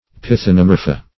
Search Result for " pythonomorpha" : The Collaborative International Dictionary of English v.0.48: Pythonomorpha \Pyth`o*no*mor"pha\, n. pl.
pythonomorpha.mp3